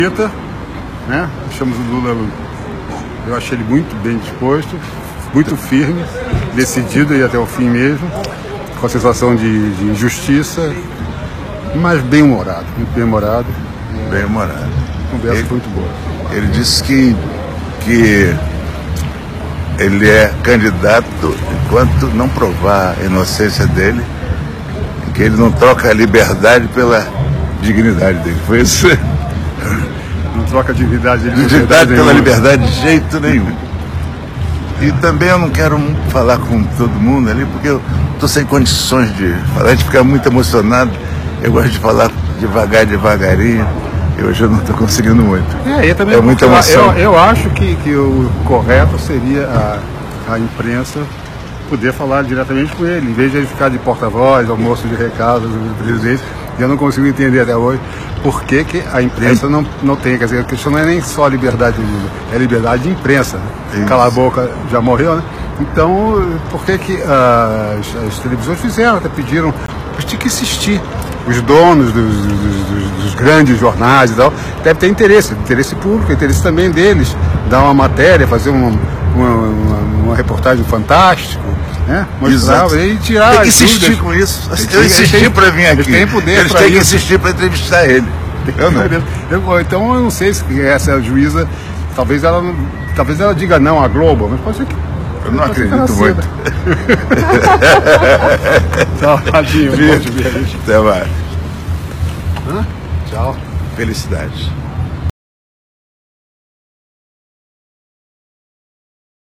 O pré-candidato à Presidência da República, Luiz Inácio Lula da Silva (PT), recebeu na quinta-feira (2/8) a visita de Chico Buarque e Martinho da Vila, na sede da Polícia Federal do Paraná. Após o encontro, os músicos reafirmaram que Lula garantiu que é candidato à eleição.